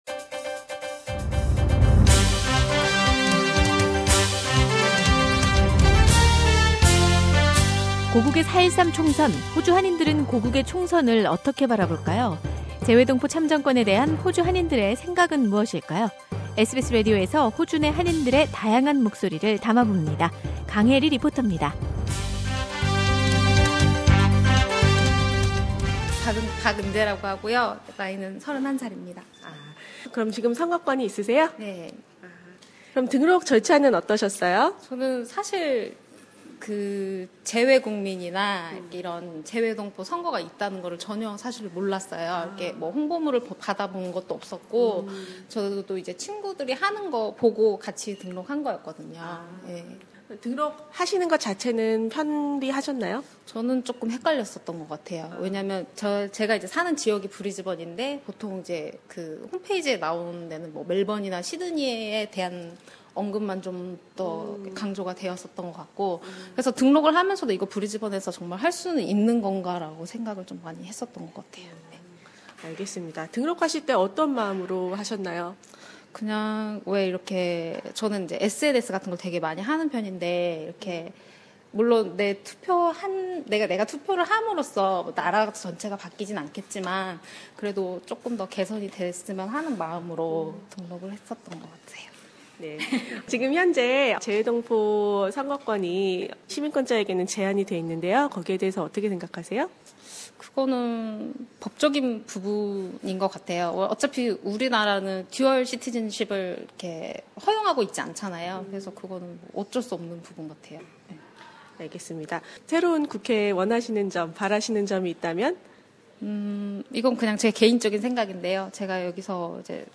Korean Progam presents a special voxpop series with those who have cast a vote in Australia for the Korea's general election which takes place on 13 April in Korea, to hear about their percetion on the overseas voting system and desire for Korean politicians.